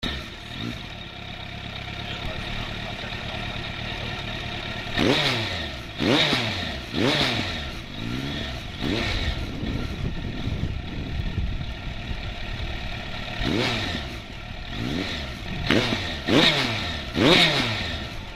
Quelques sons de pots au format mp3: